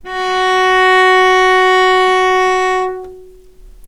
healing-soundscapes/Sound Banks/HSS_OP_Pack/Strings/cello/ord/vc-F#4-mf.AIF at b3491bb4d8ce6d21e289ff40adc3c6f654cc89a0
vc-F#4-mf.AIF